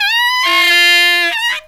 63SAXFALL2-R.wav